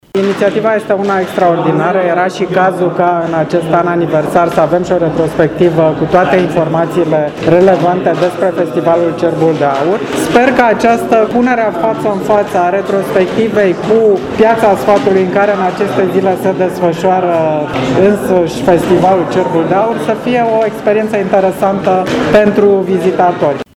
Evenimentul a avut loc la Casa Sfatului, în centrul istoric al Brașovului.